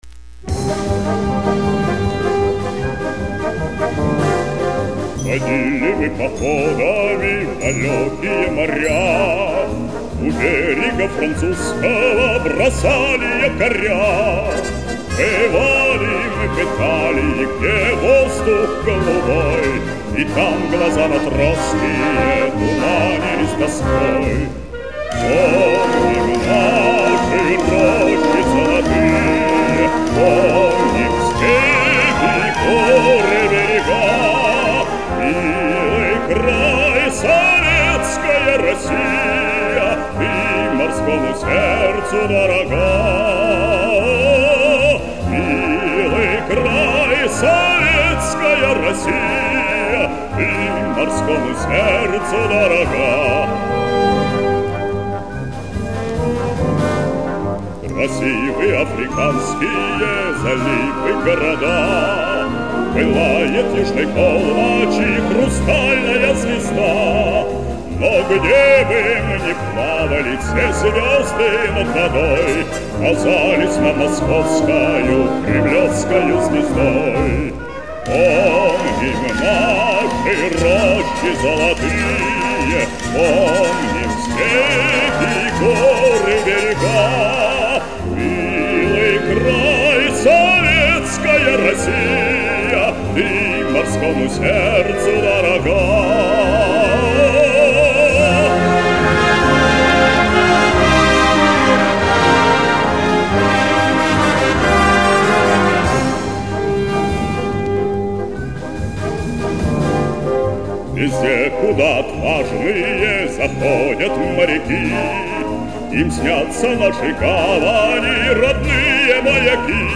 Пожалуй, одна из лучших песен о морской службе Родине